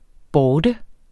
Udtale [ ˈbɒːdə ]